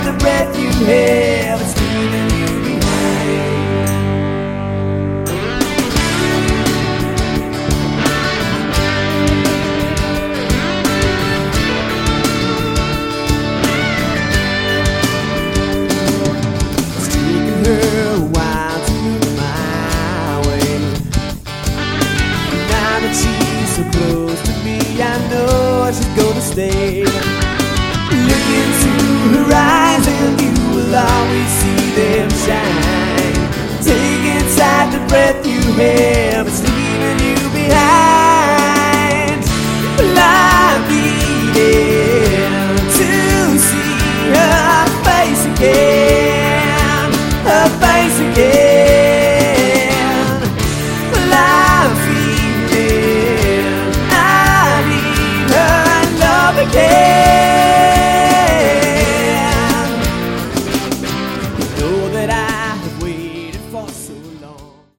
Category: AOR
vocals, guitars, keys
bass, vocals
drums, vocals